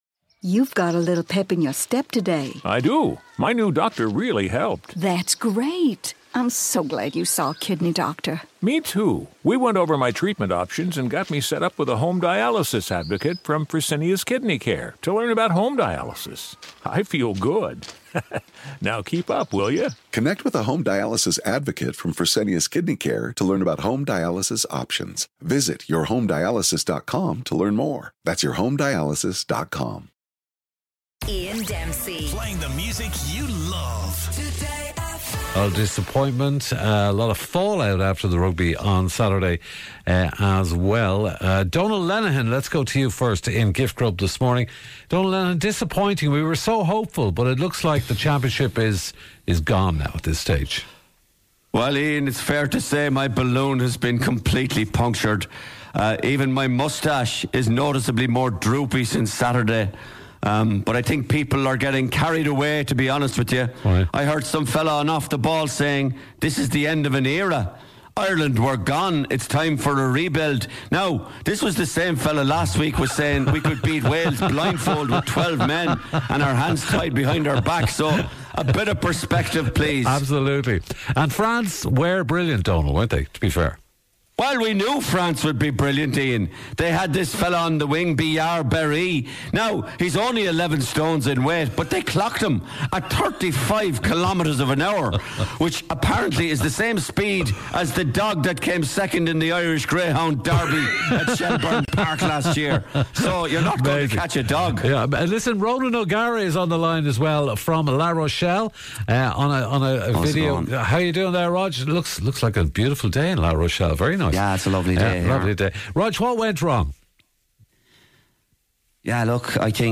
Comedy News and Entertainment Entertainment Ireland Today FM Morgan Freeman